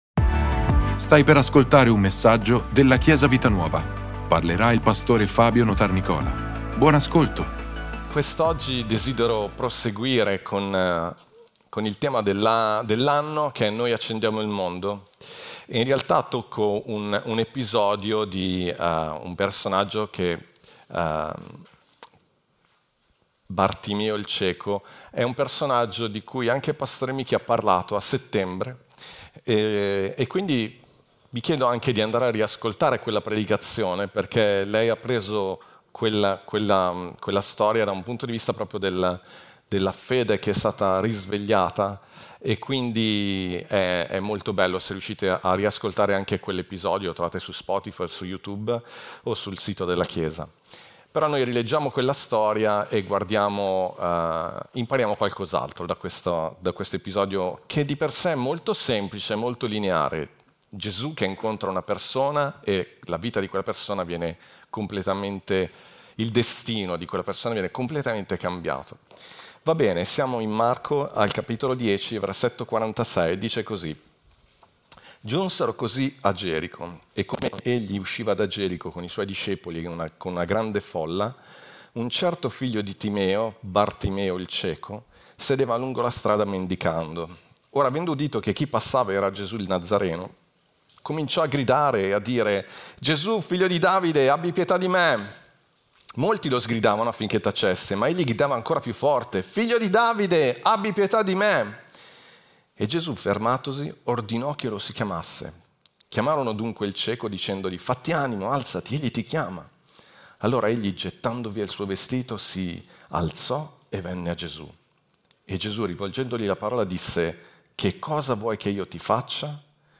Ascolta la predicazione: 05/26_Cosa vuoi davvero? - Chiesa Vita Nuova